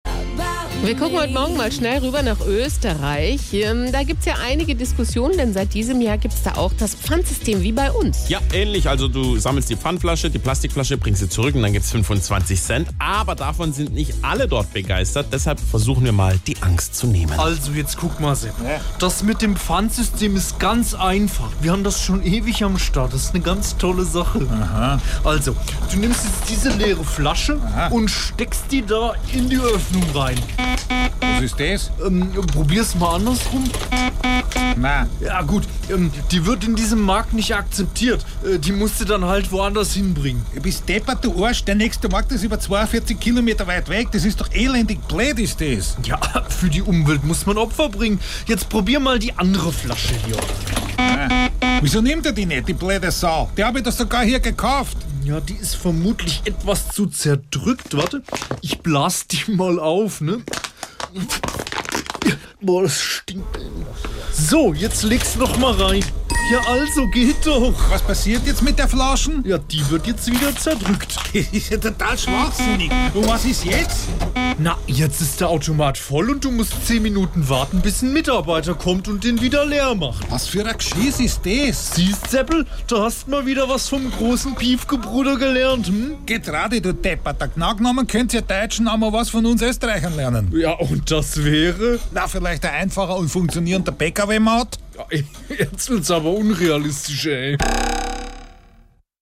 SWR3 Comedy Ösis lernen How to Pfand